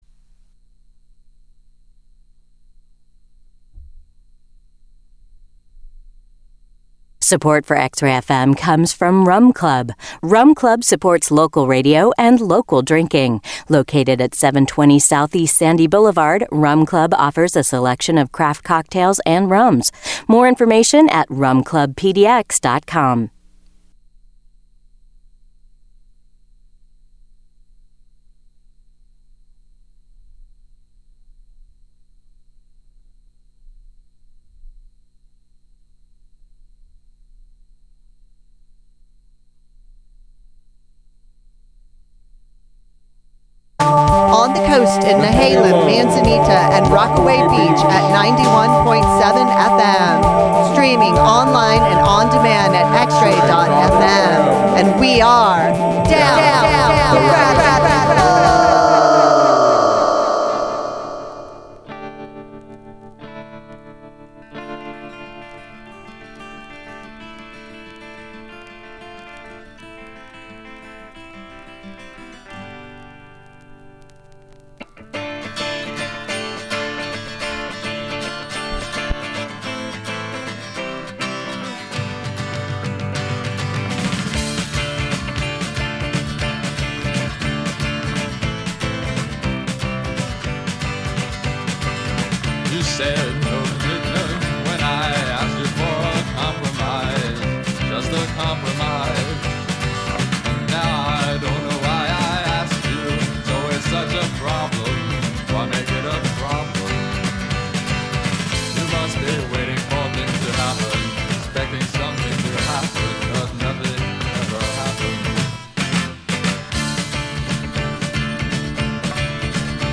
The stranger side of underground music.